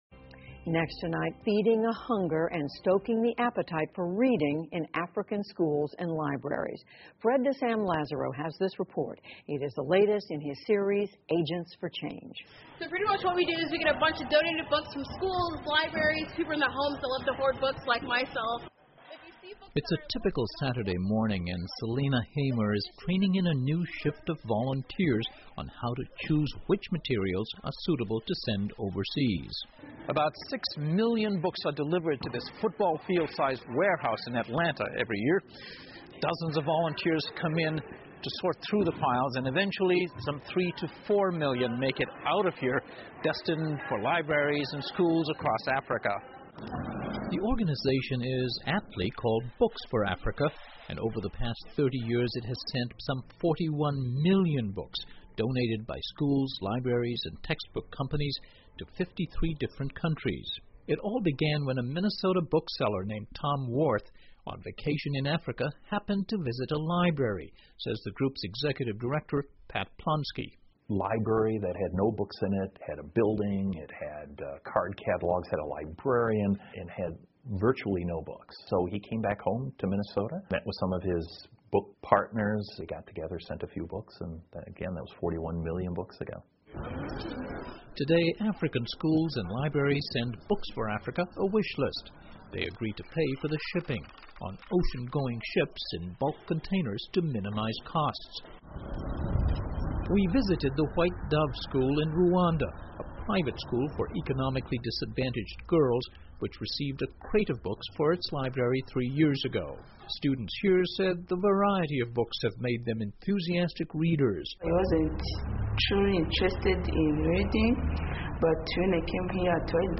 PBS高端访谈:美国为非洲援赠图书 激发当地阅读兴趣 听力文件下载—在线英语听力室